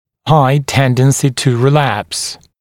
[haɪ ‘tendənsɪ tə rɪ’læps][хай ‘тэндэнси ту ри’лэпс]высокая тенденция к рецидиву